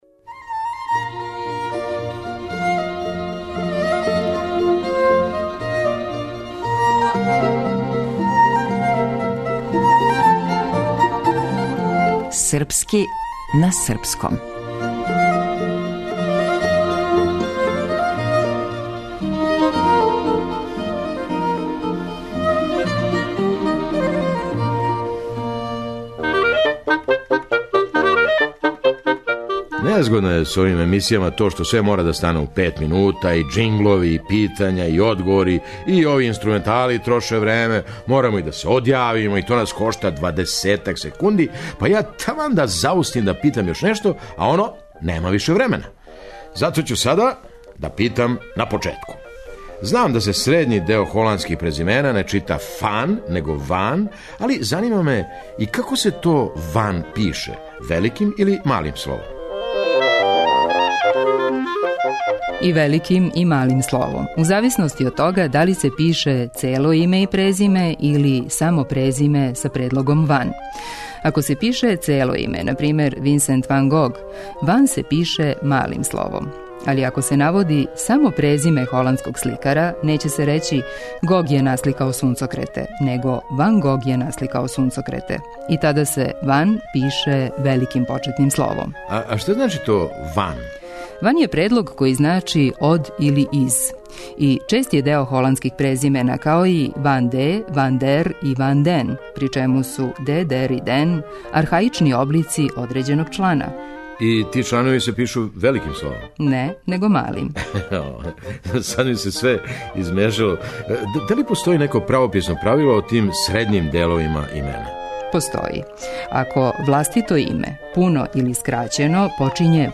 Незгодно је с овим емисијама то што све мора да стане у пет минута, и џинглови и питања и одговори, и ови инструментали троше време, морамо и да се одјавимо – и то нас кошта двадесетак секунди, па ја таман да заустим да питам још нешто, а оно – нема више времена.
Драмски уметник: Феђа Стојановић